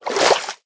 swim2.ogg